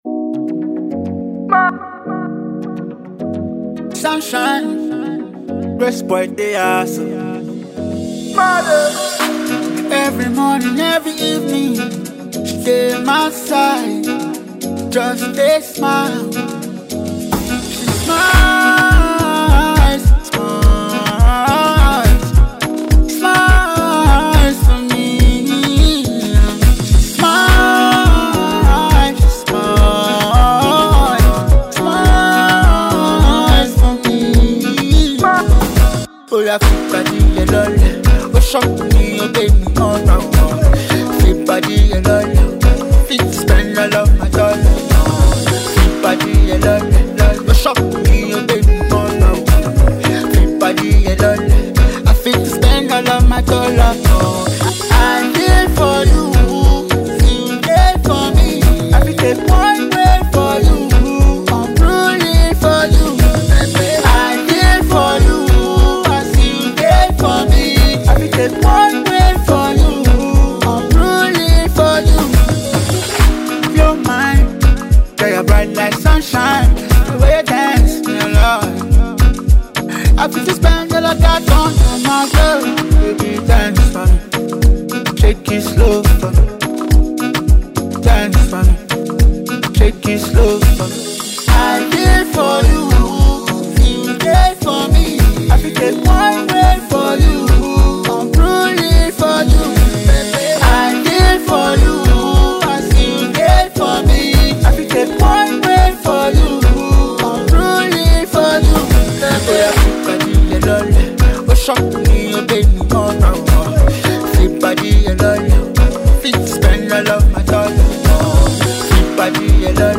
Fast-rising singer
sweet melodious sound